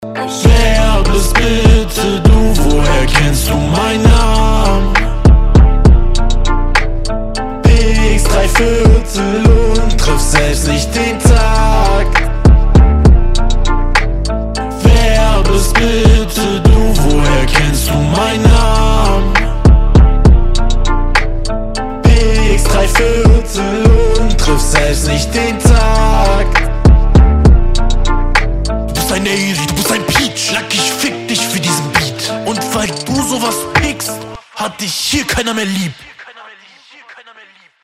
Ist ja nur eine Hook, diese grundsätzlich etwas gewöhnungsbedurftig klingt.
Warte 2010 ruft gerade an .. die wollen ihr Autotune zurück.